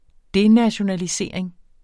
Udtale [ ˈdenaɕonaliˌseɐ̯ˀeŋ ]